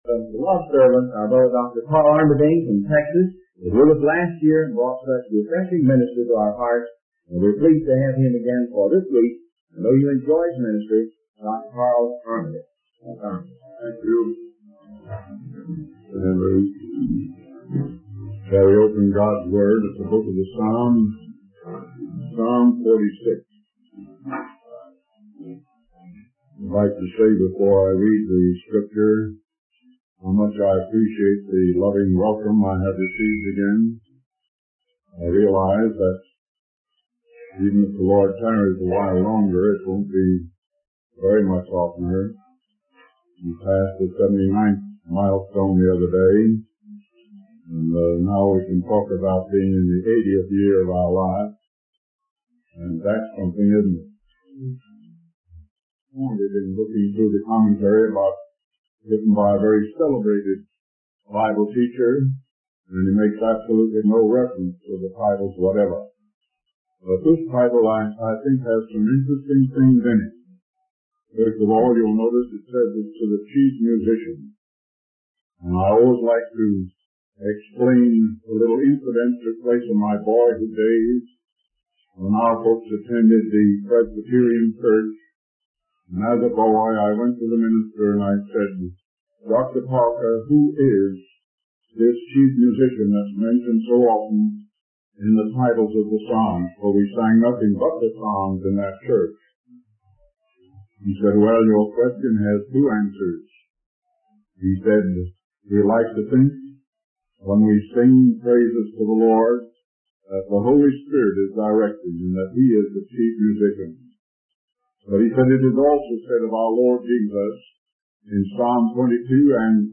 In this sermon, the speaker shares a personal anecdote about a young boy who was fascinated by a particular object. The speaker then relates this story to the idea of putting our trust in God. He emphasizes the importance of believing in God and having faith in Him.